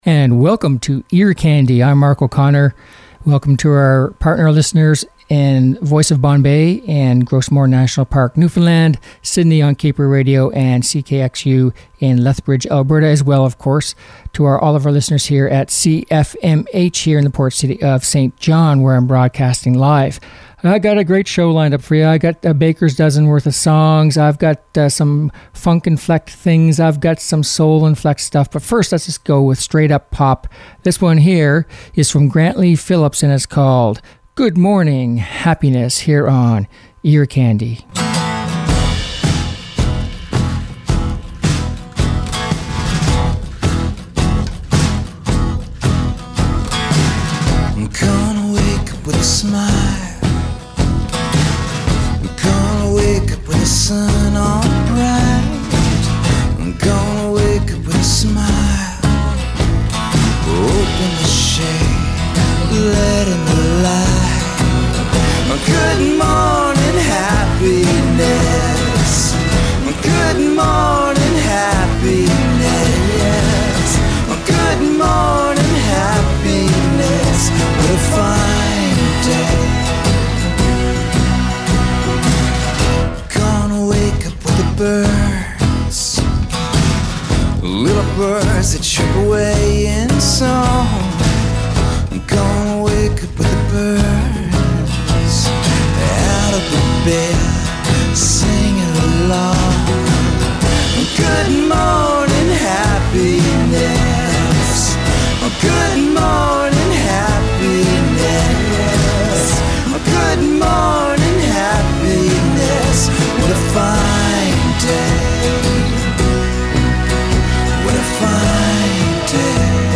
Upbeat Pop Songs